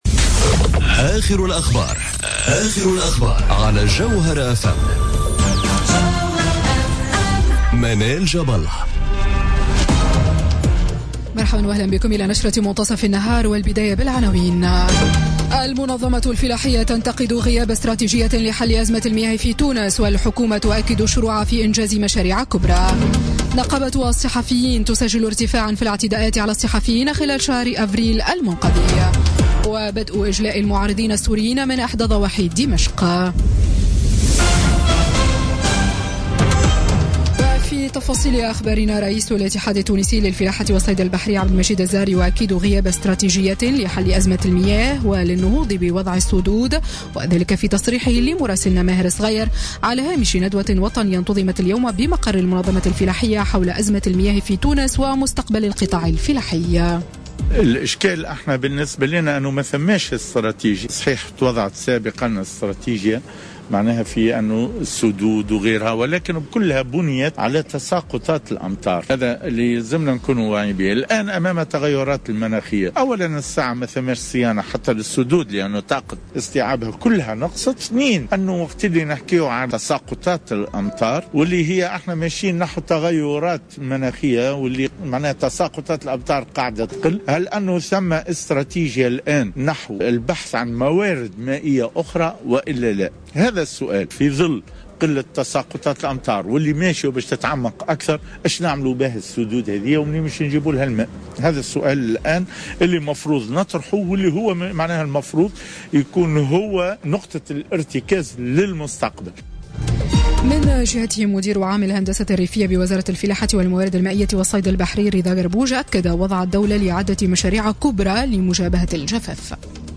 نشرة أخبار منتصف النهار ليوم الإثنين 8 ماي 2017